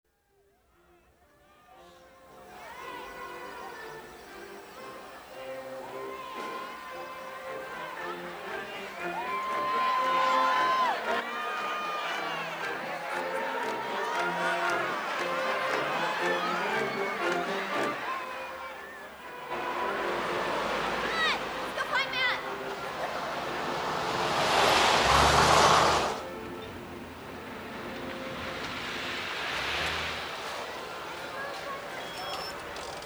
音声データ　街の開拓時代祭りでパレード